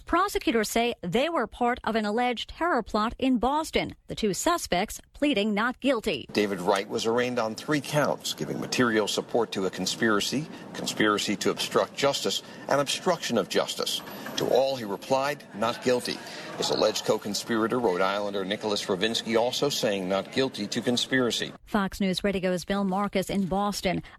5pm-LIVE-Fox-News-Radio.mp3